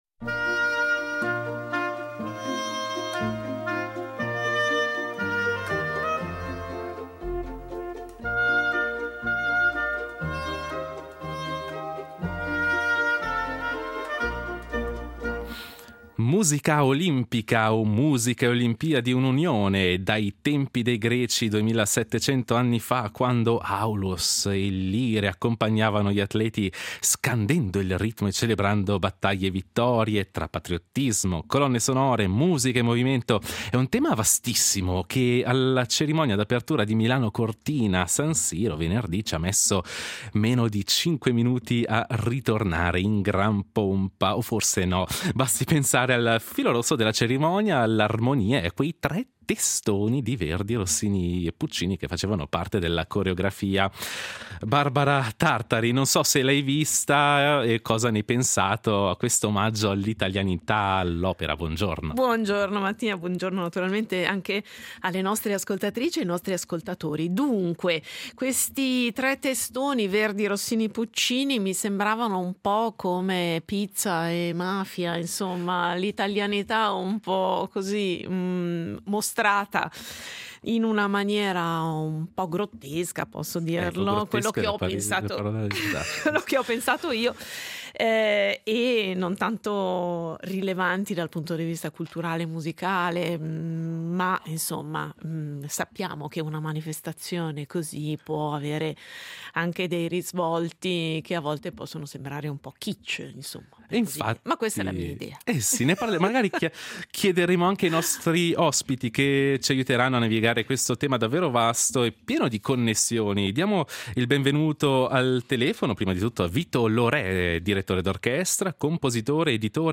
Un viaggio tra colonne sonore di grandi eventi scritte nei secoli, patriottismo e trionfo.